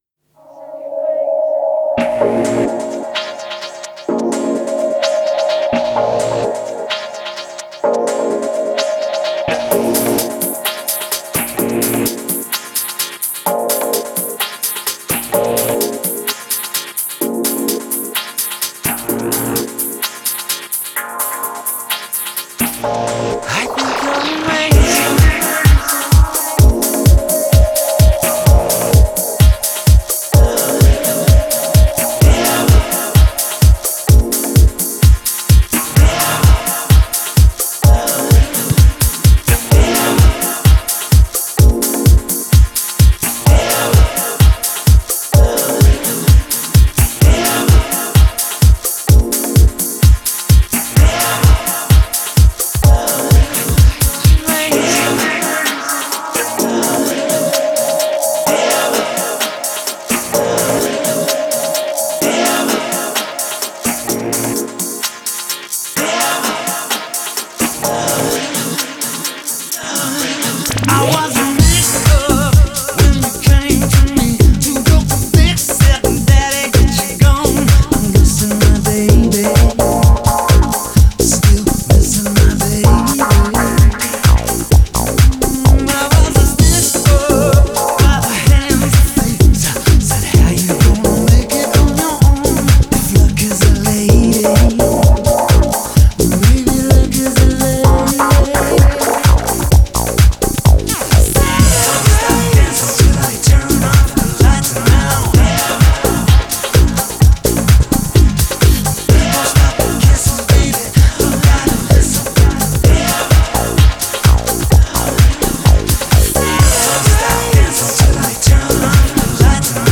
популярный британский певец.